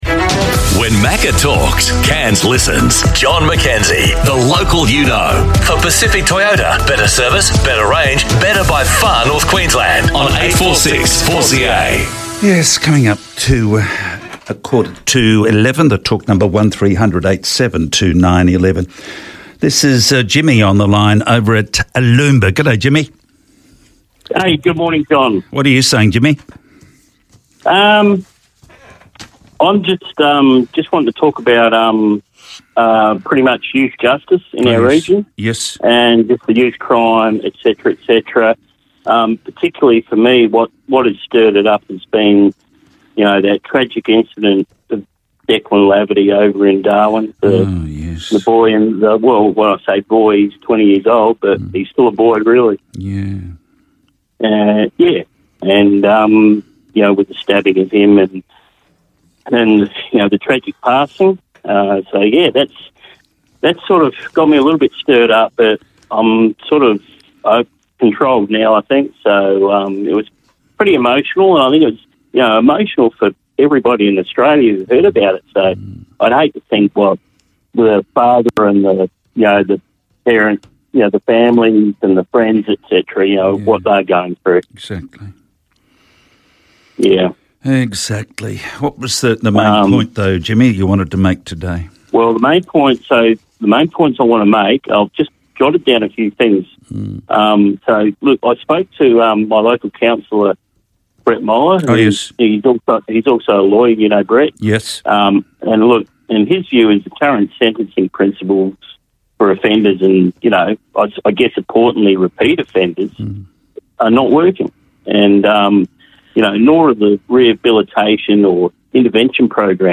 talkback caller